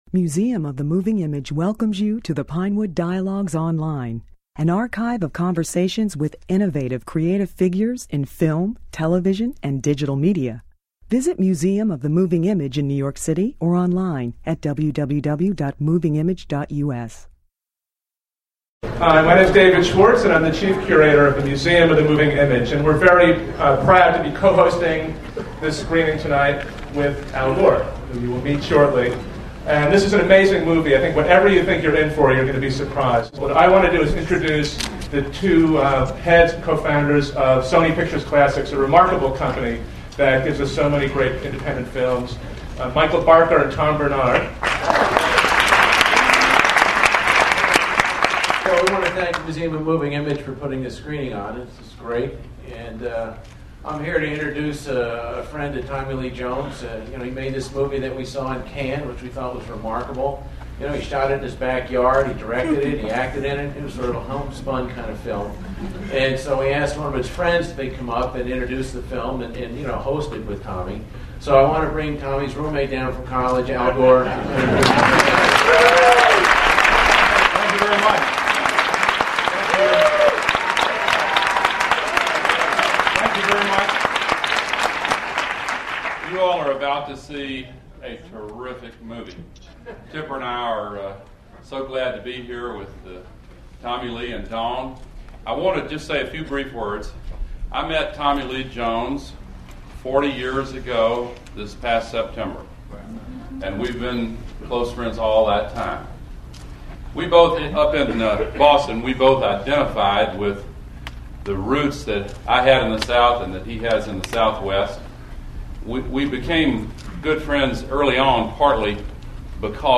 The movie had its New York premiere on a cold December night at a special screening co-hosted by the Museum of the Moving Image and Jones's Harvard roommate, former Vice President Al Gore.